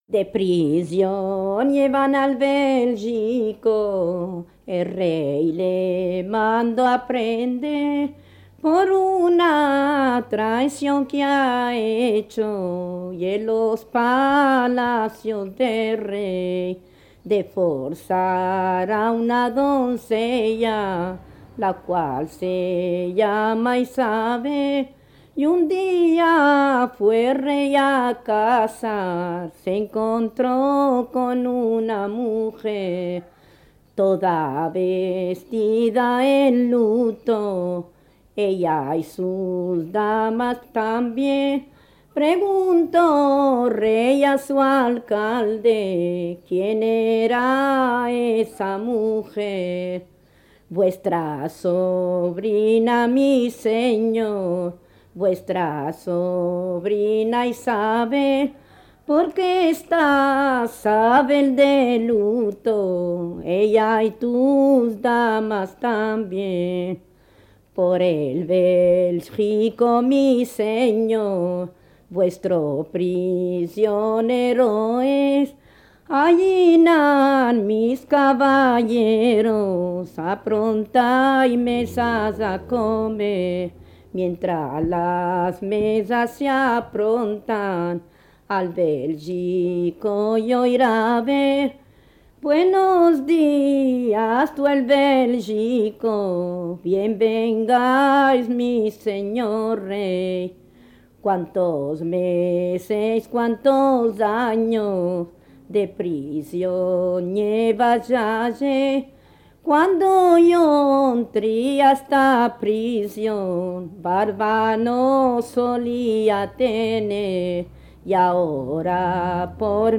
0178:2 Mambrú (á) (ficha no.: 2) Versión de Santa Marta del Cerro (ay.
Recitada
Música registrada.